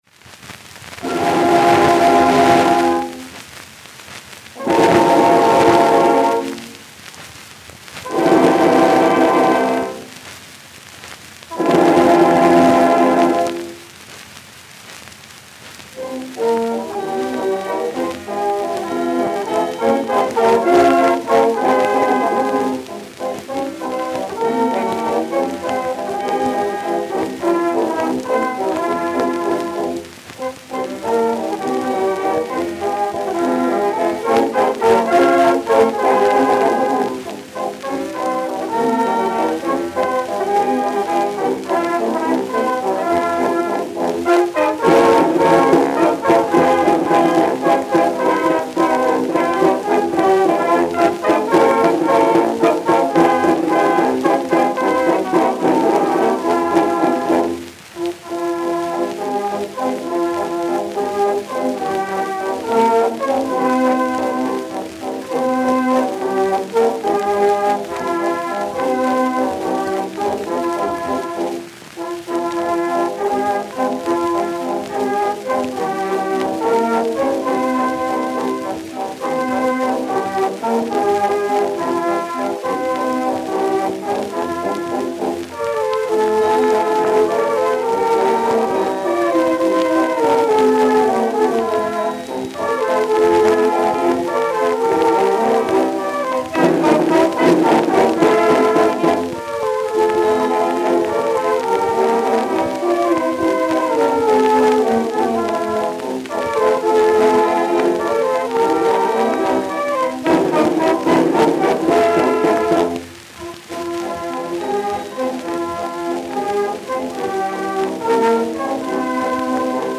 Исполнитель: Оркестр 1-го Сумского Гусарского полка